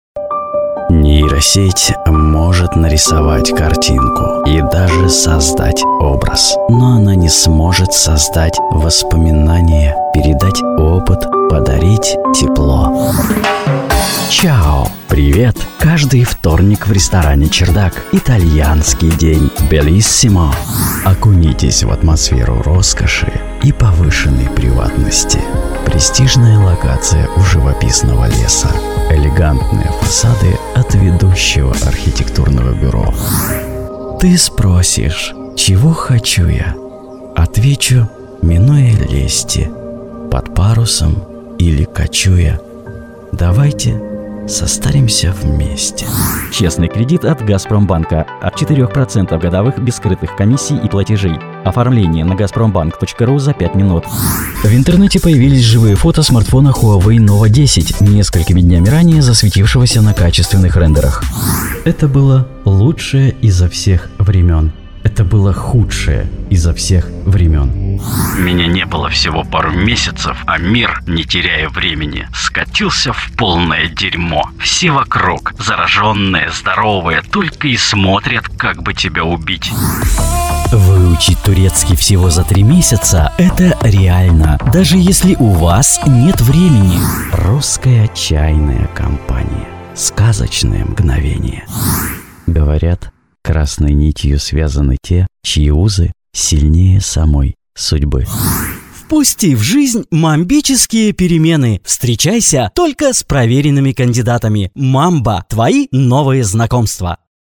Пример звучания голоса
Муж, Рекламный ролик/Зрелый
Приятный средний тембр (могу ниже). Молодой/взрослый, с хрипотцой. Иногда спокойный и задумчивый, иногда весёлый и позитивный, агрессивный или с нотами иронии/сарказма.
Дикторская кабина.